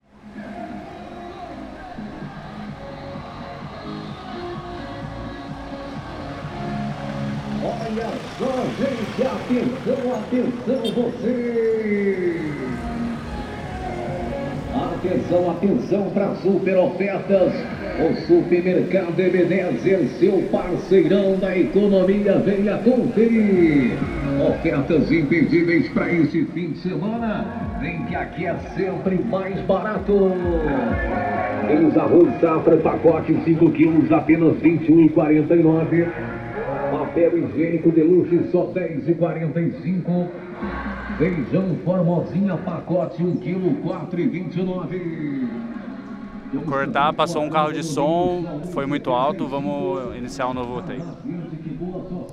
CSC-16-020-OL- Carro de som em cidade de São Domingos de manhã.wav